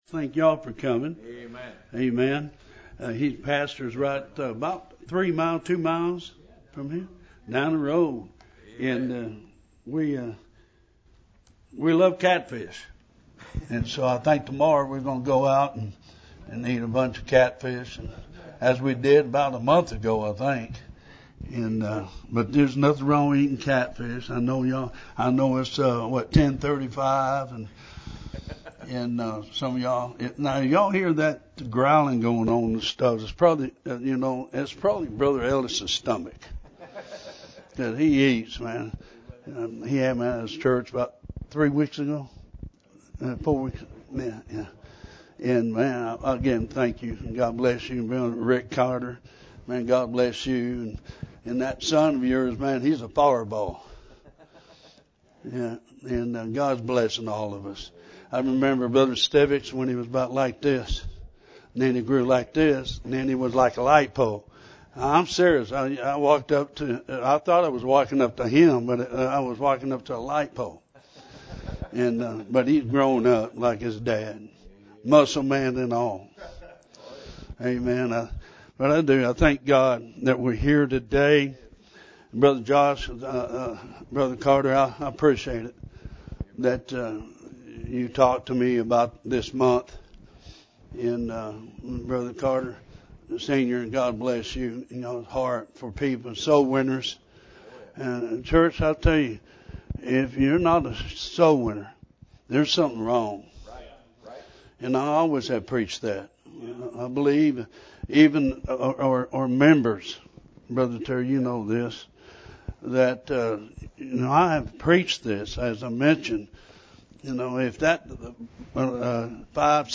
(audio issues at the 65 minute mark)